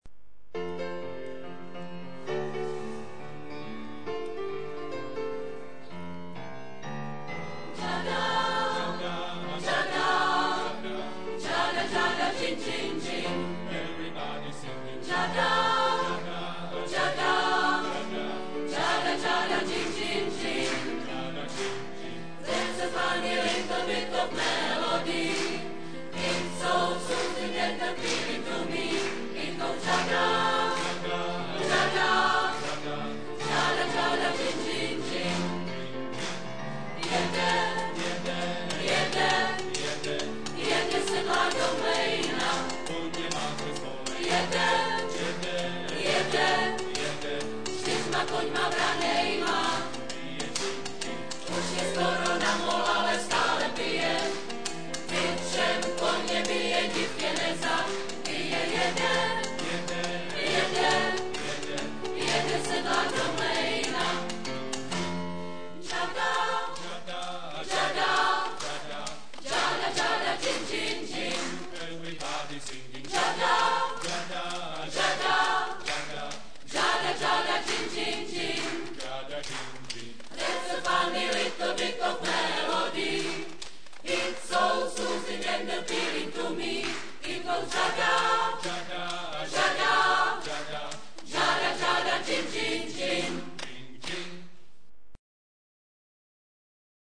* REPERTOÁR,mp3,videa * :: OTAKAR - smíšený pěvecký sbor VYSOKÉ MÝTO